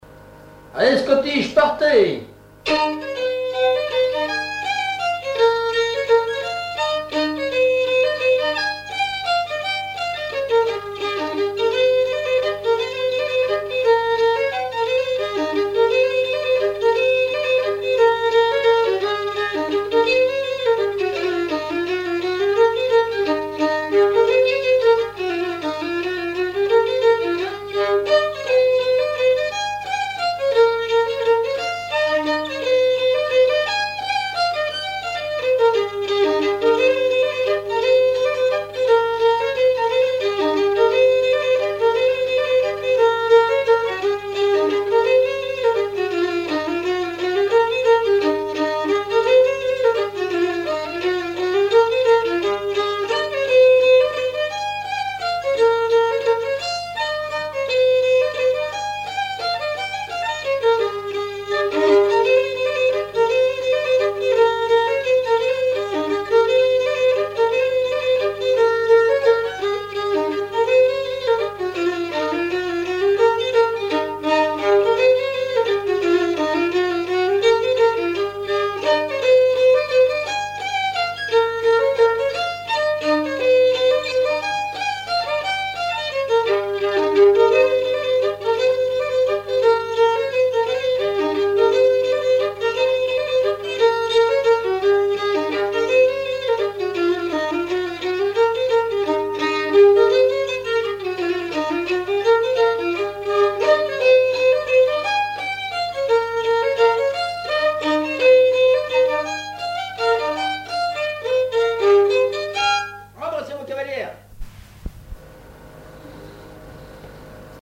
danse : scottich trois pas
Auto-enregistrement
Pièce musicale inédite